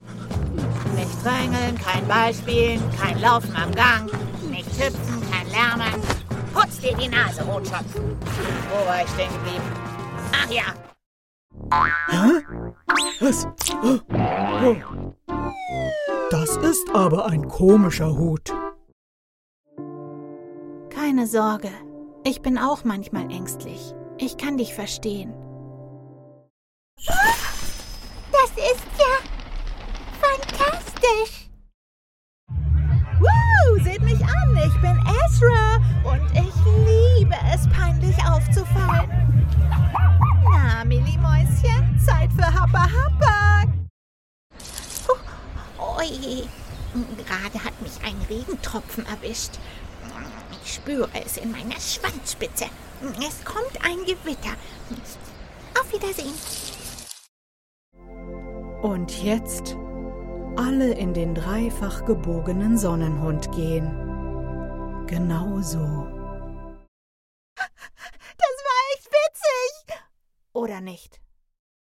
Synchron – Trickfilmchargen (Medley)
mitteltief ⋅ frisch ⋅ facettenreich
Horprobe-Trickfilmcharge.mp3